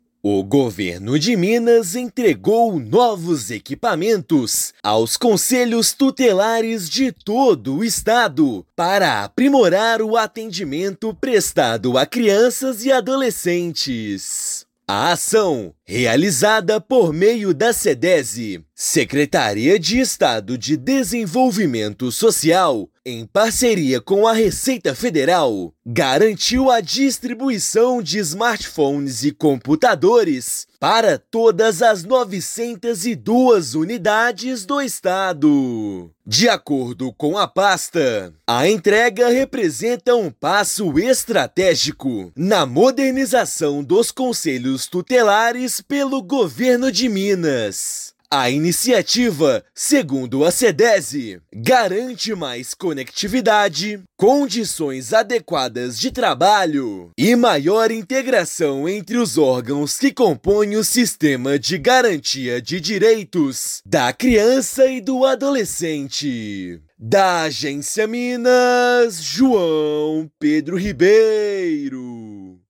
[RÁDIO] Governo de Minas entrega smartphones e computadores para os 902 Conselhos Tutelares do estado
Ação, em parceria com a Receita Federal, leva tecnologia e melhoria no atendimento às crianças e adolescentes. Ouça matéria de rádio.